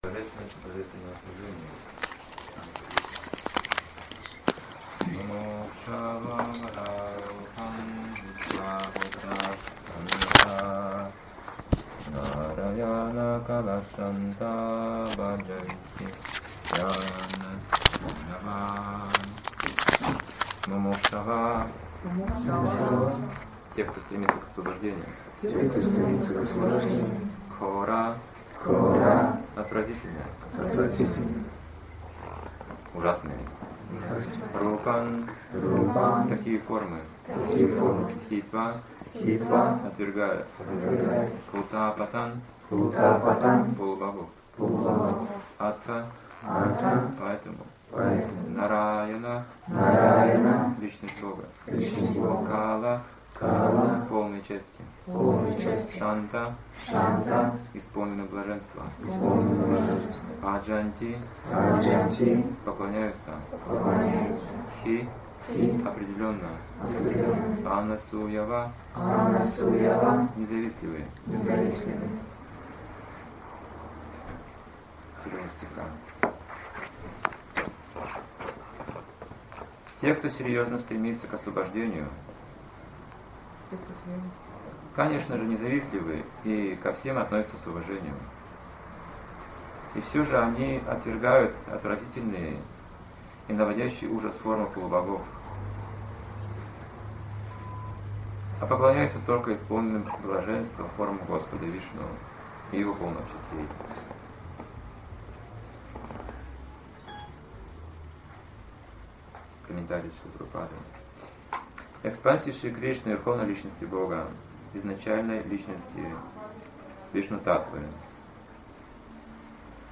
Темы затронутые в лекции: